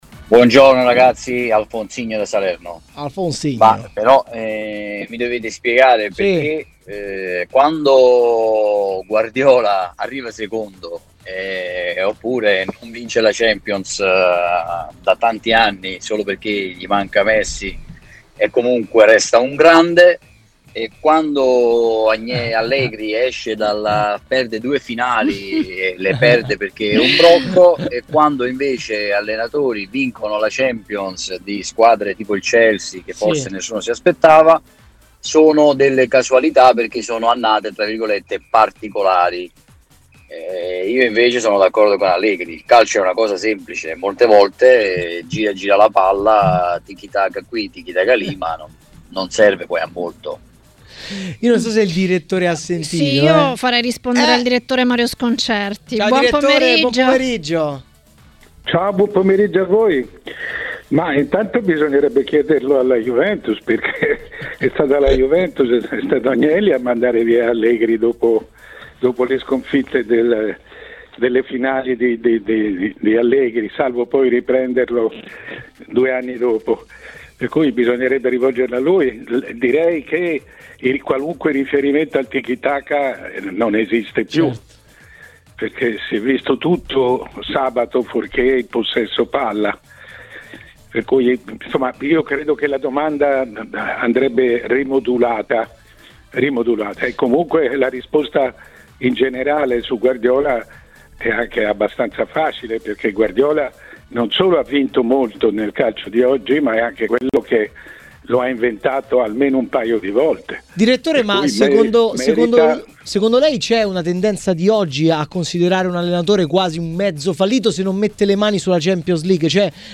Il direttore Mario Sconcerti a Maracanà, nel pomeriggio di TMW Radio, ha detto la sua sulle tematiche del giorno.